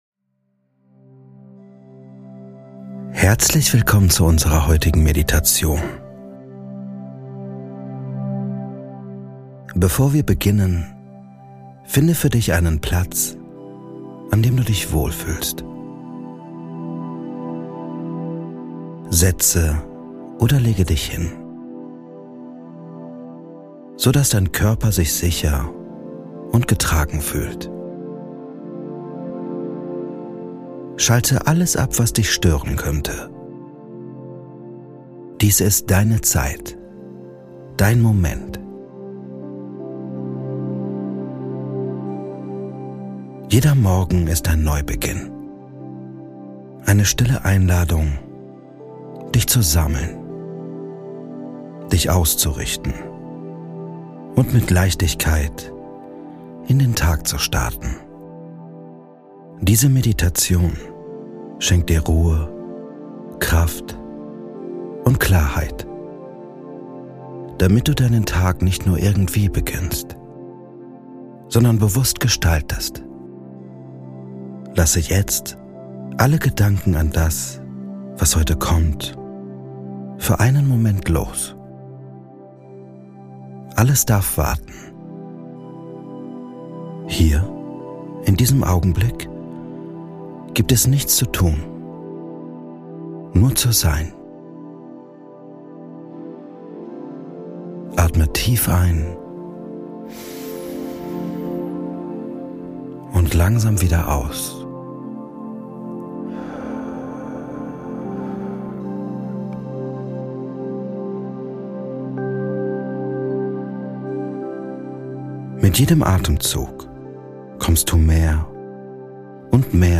Deine Morgen Meditation für mehr Energie und Gelassenheit - Ein klarer Start in den Tag ~ Kopfkanal - Geführte Meditationen Podcast